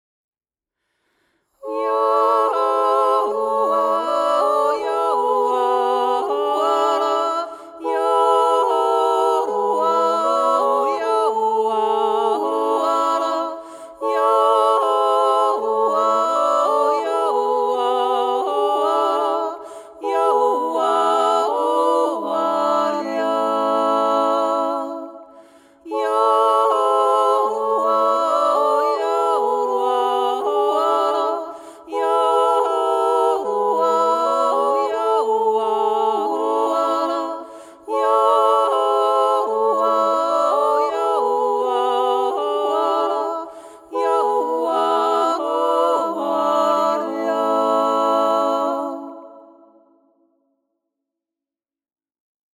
yinyang-yodler.mp3